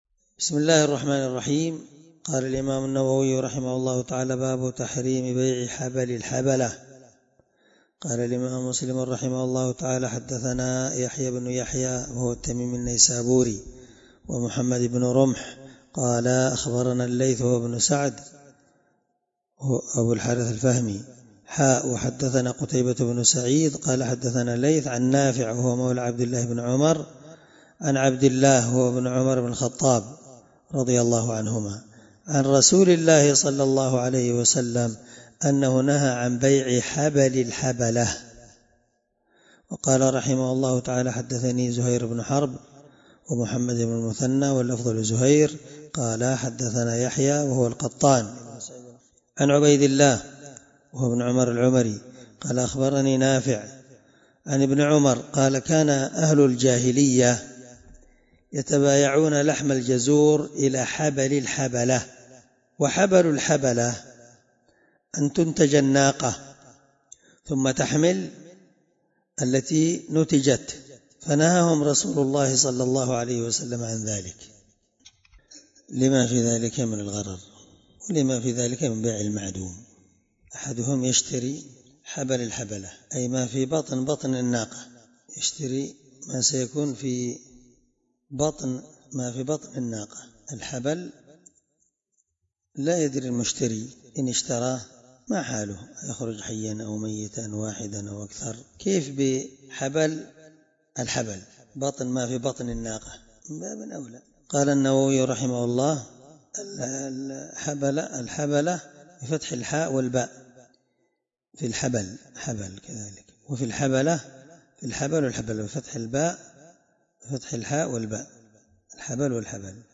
الدرس3من شرح كتاب البيوع حديث رقم(1514) من صحيح مسلم